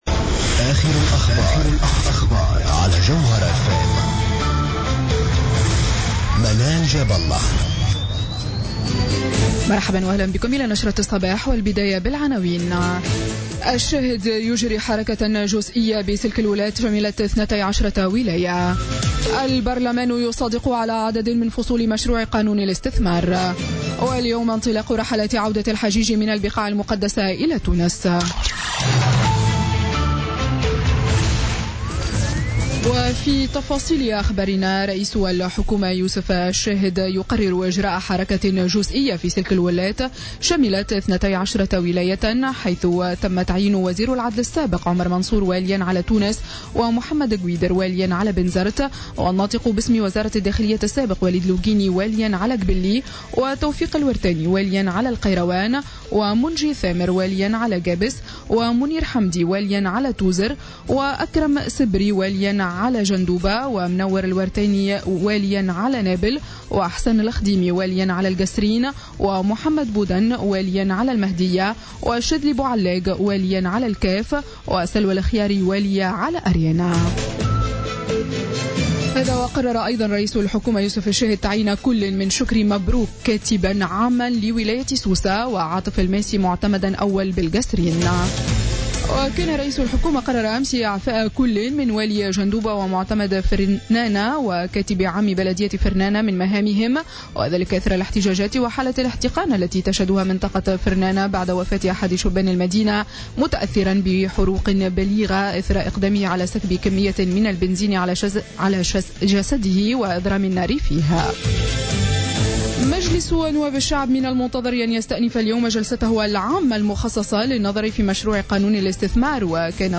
نشرة أخبار السابعة صباحا ليوم السبت 17 سبتمبر 2016